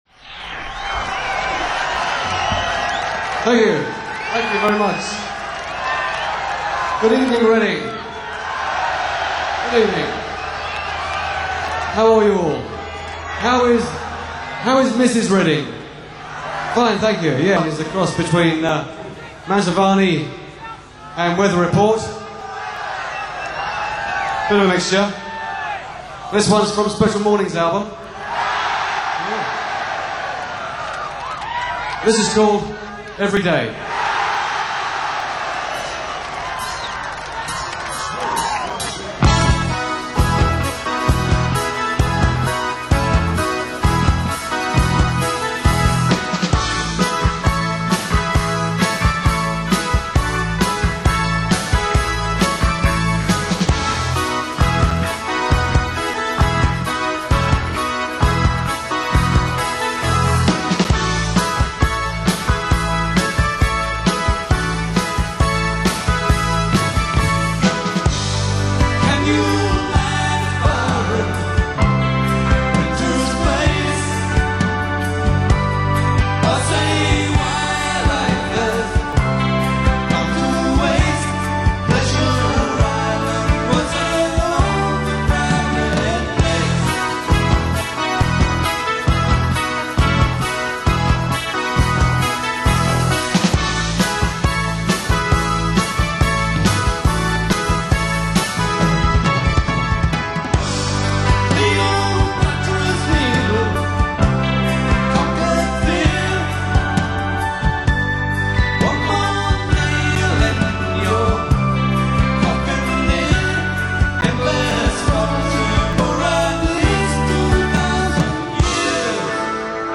in concert
Legends of Progressive Rock in concert.